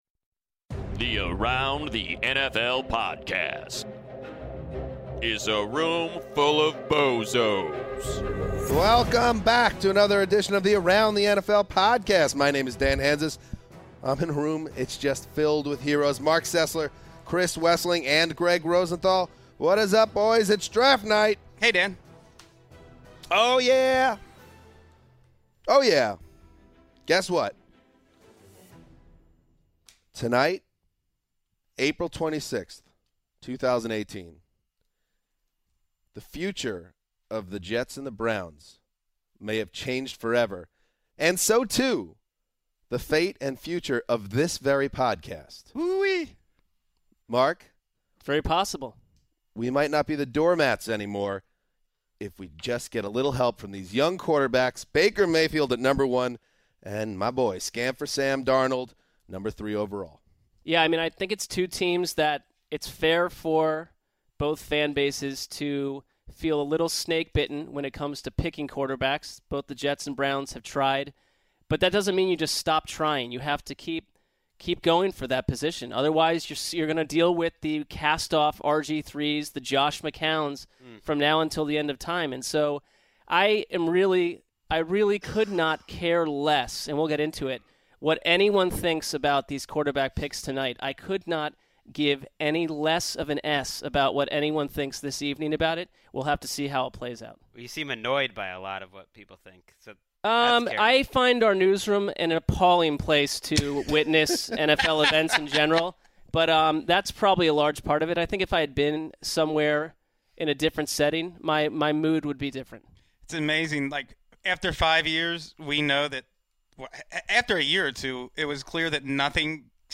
assemble in-studio to recap the first round of the 2018 NFL Draft, starting with the Top 10.